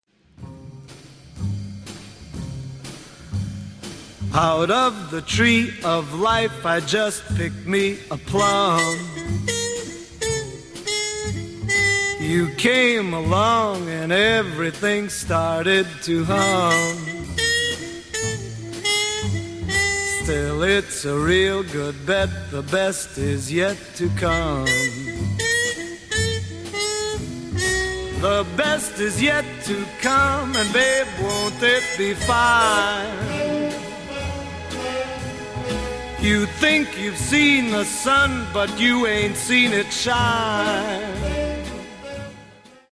muchísimo mejor y más alegre